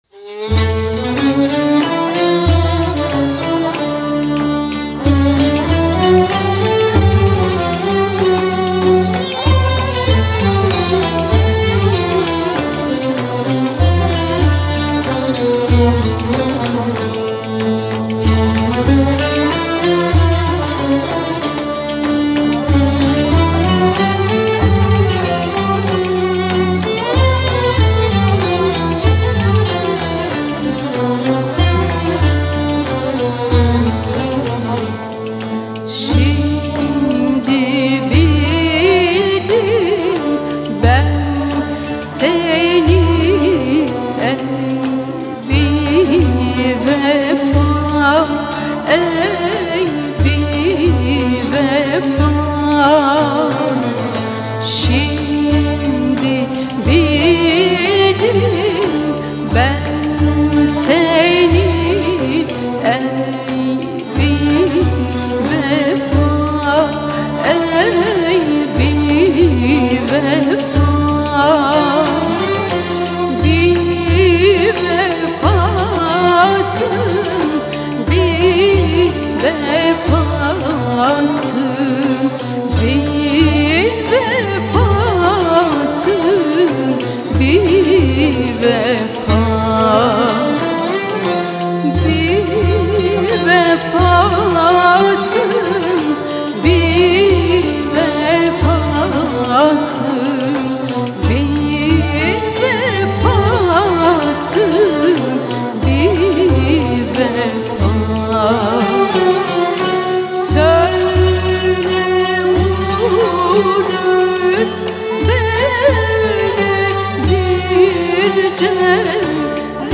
Eser: Şimdi bildim ben seni ey bi-vefa Bestekâr: Ahmet Irsoy (Hafız) Güfte Sâhibi: Belirsiz Makam: Isfahan Form: Şarkı Usûl: Devr-i Hindi Güfte: -...